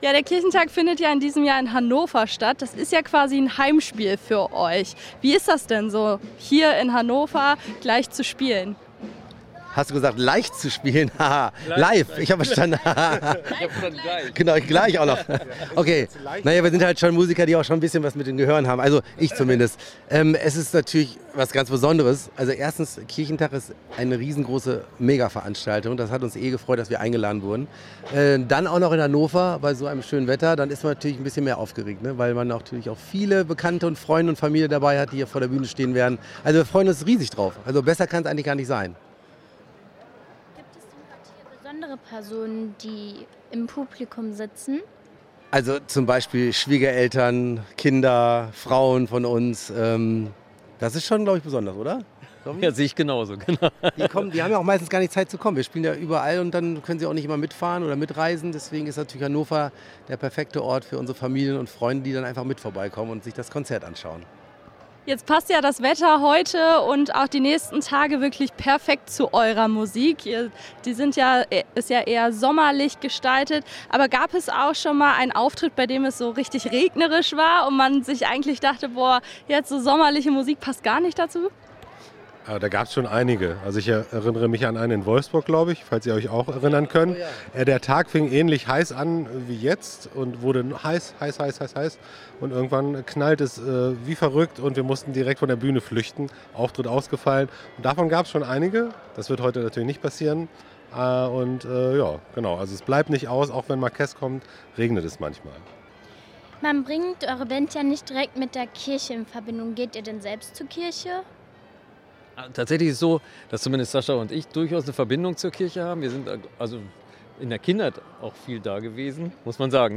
Das Interview fand auf dem Kirchentag in Hannover statt. Es ging vor allem um ihre Musik und das Heimspiel vor ihrem Auftritt in Hannover.